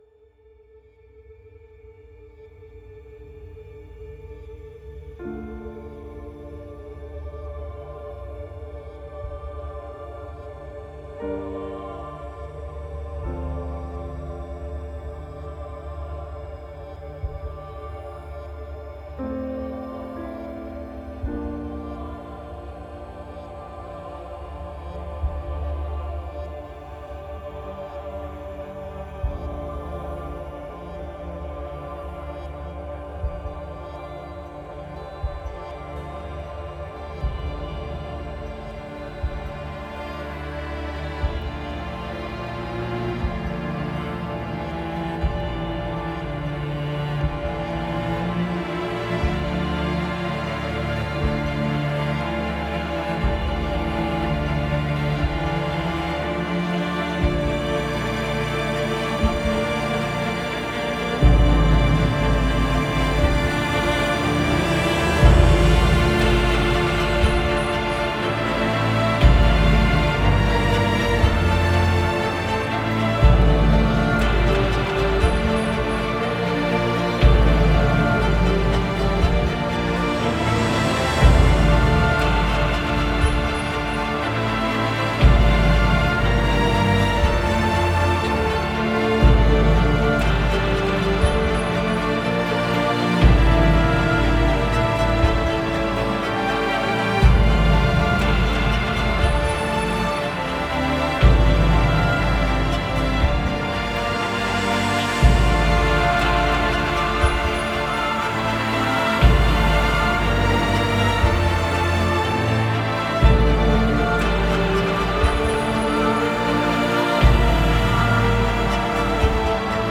موسیقی متن موسیقی بیکلام